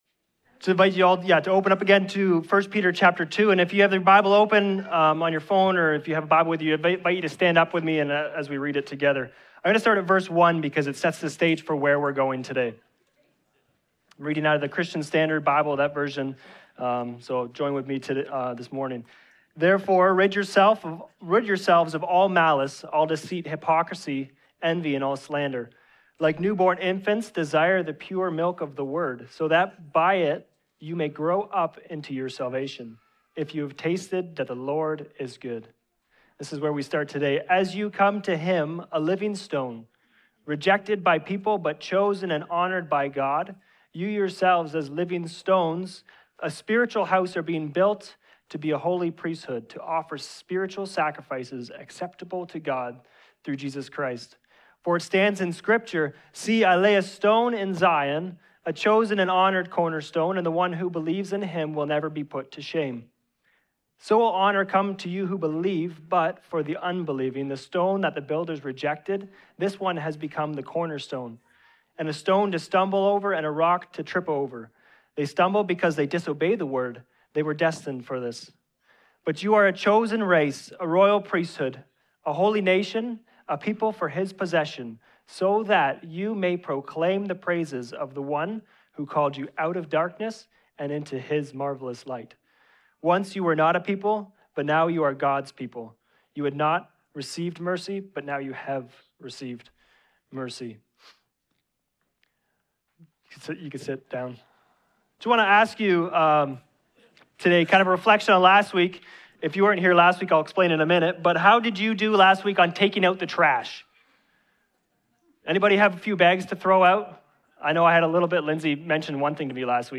The sermon highlights three powerful truths from the passage: we are built on Christ as our foundation, many still reject Him and stumble over the truth, and those who believe are called by God’s mercy into a new identity.